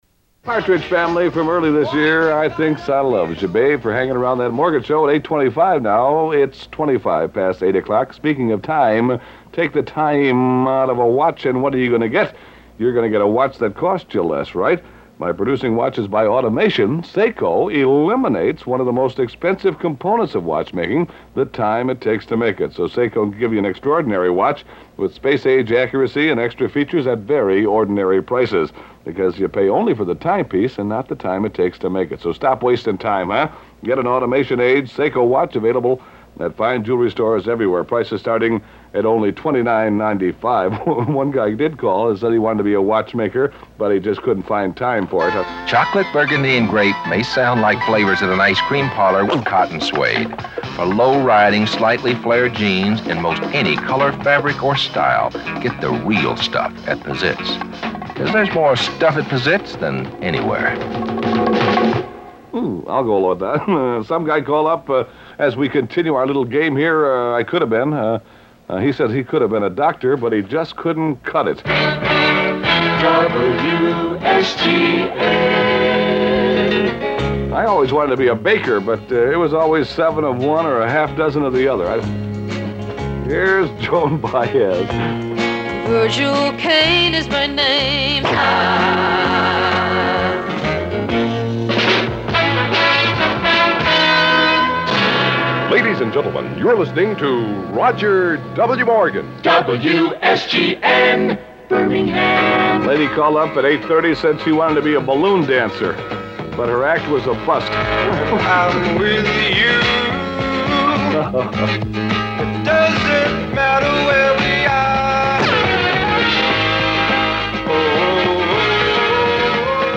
(We don't have audio of RWM from 1970, so we offer as a 'bonus' this amusing August '71 aircheck ) Desperate Housewives, circa 1970.....